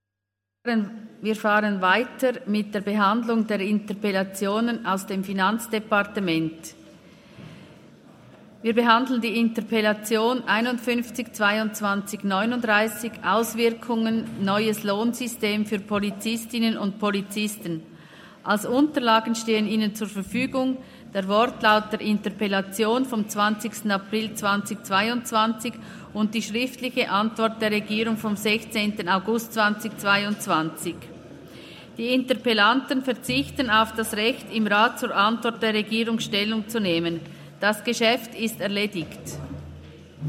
Session des Kantonsrates vom 27. bis 29. November 2023, Wintersession